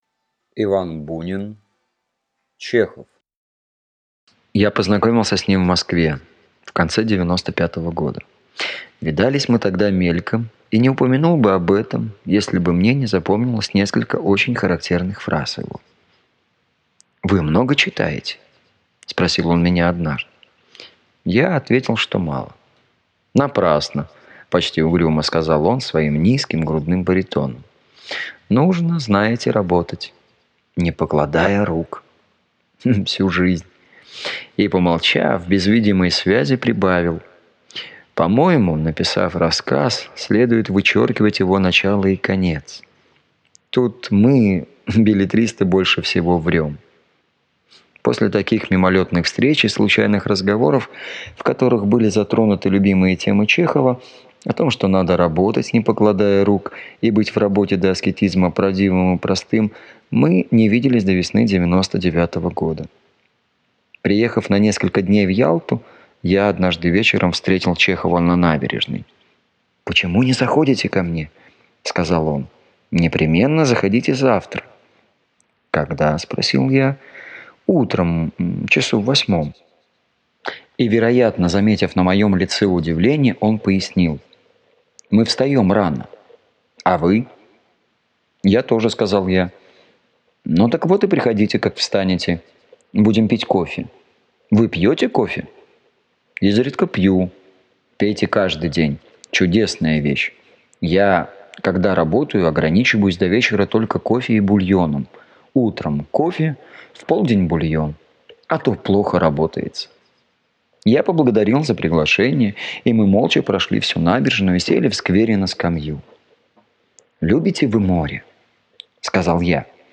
Аудиокнига Чехов | Библиотека аудиокниг